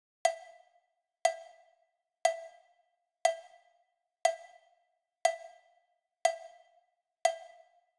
29 Cowbell.wav